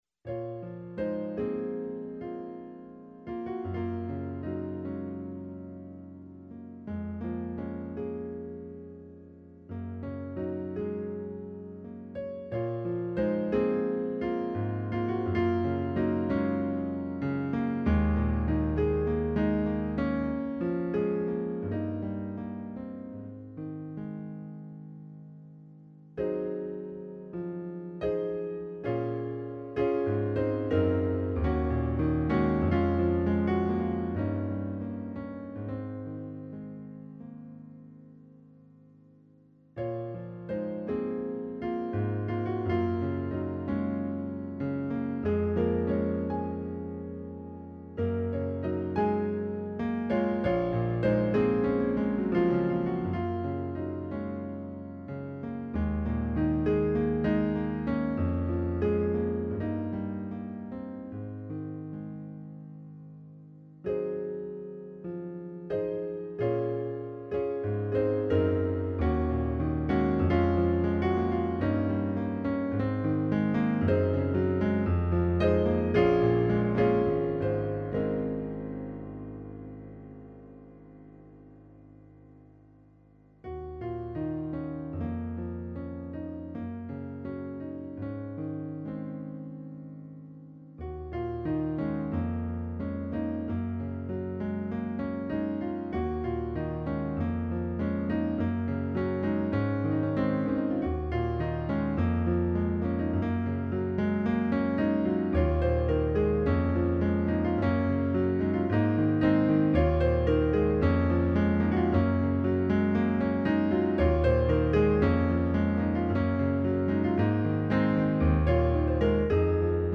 A short, melodic piano instrumental, using just a few iterations of a few chords.